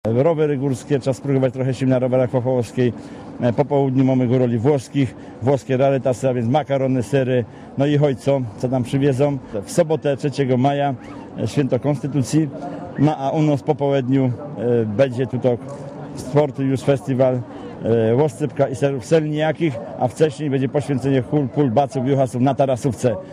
Zaprasza Andrzej Gąsiennica Makowski- starosta tatrzański